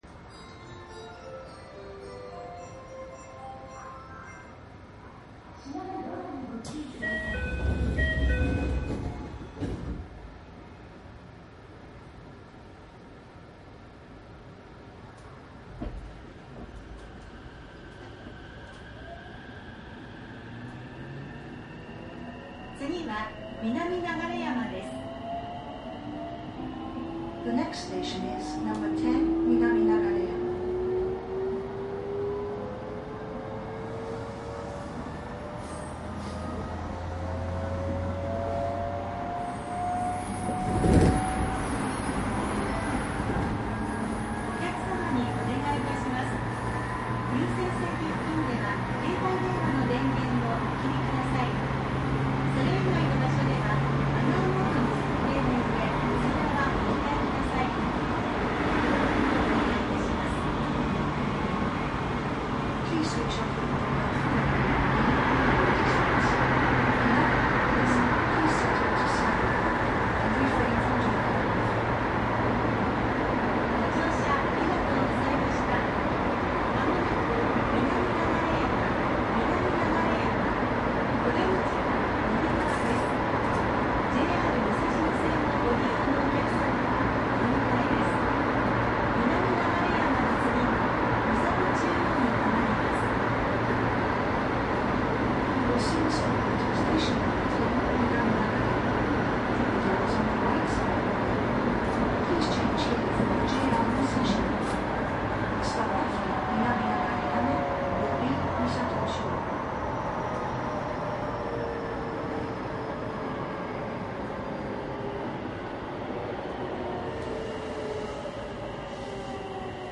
つくばエクスプレスTX1000系走行音♪
TX1000で直流区間の各駅停車を往復録音。
マスター音源はデジタル44.1kHz16ビット（マイクＥＣＭ959）で、これを編集ソフトでＣＤに焼いたものです。